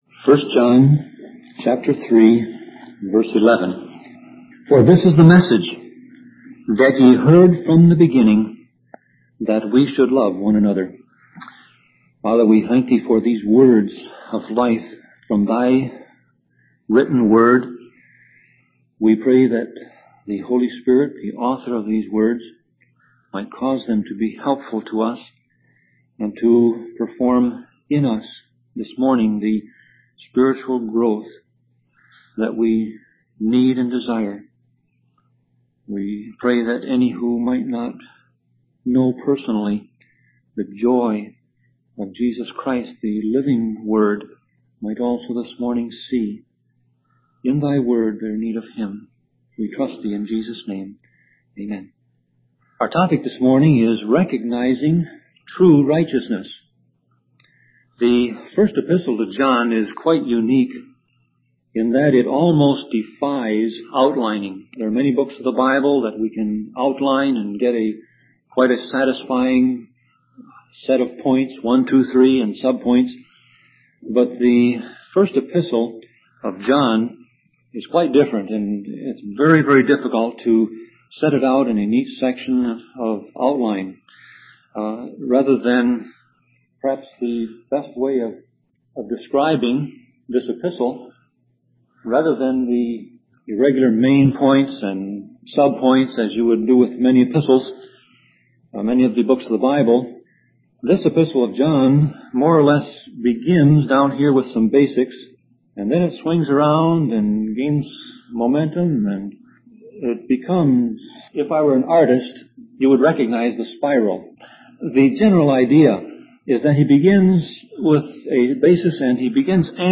Sermon Audio Passage: 1 John 3:11 Service Type